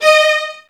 VIOLINS..2-R.wav